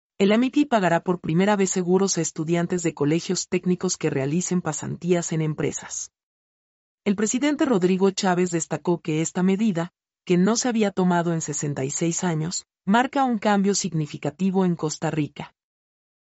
mp3-output-ttsfreedotcom-95-1.mp3